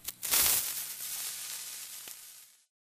fuse.ogg